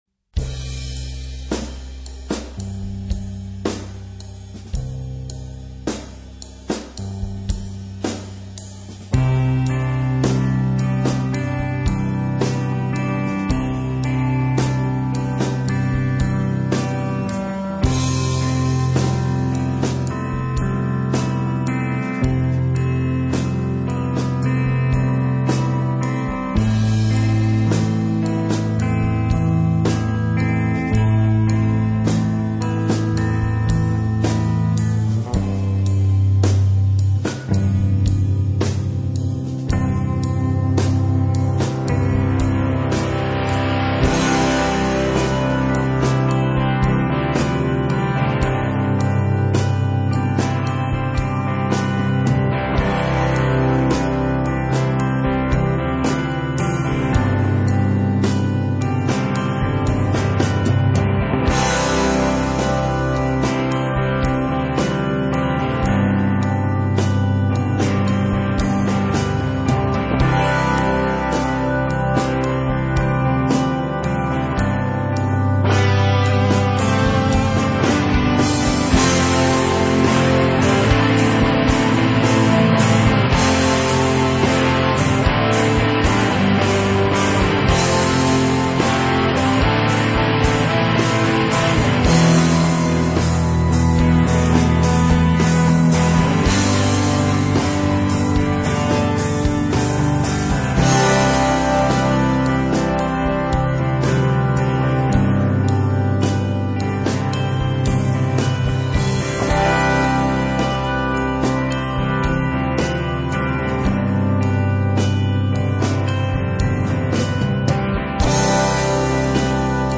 rock
metal
punk
hard rock
high energy rock and roll